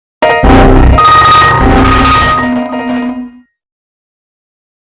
The Windows Error
error windows windowsxp sound effect free sound royalty free Memes